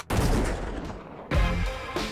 BLAST1.WAV